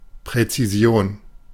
Ääntäminen
Synonyymit meaning Ääntäminen US Tuntematon aksentti: IPA : /ˌdɛfɪˈnɪʃ(ə)n/ Haettu sana löytyi näillä lähdekielillä: englanti Käännös Konteksti Ääninäyte Substantiivit 1.